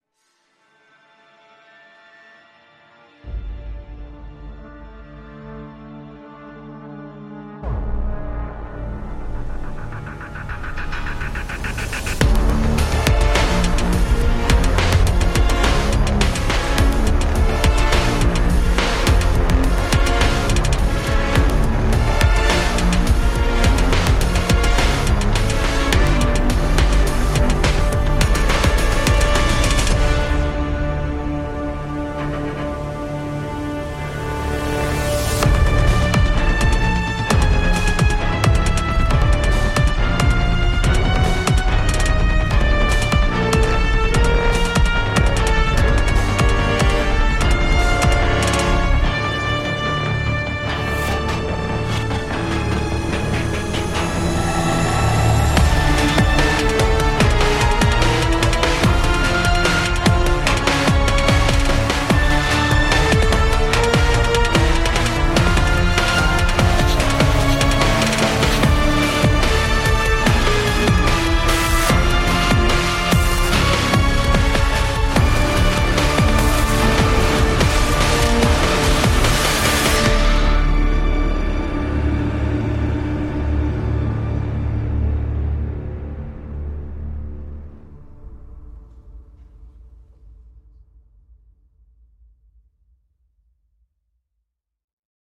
Ambiances et cavalcades bêtement orchestrales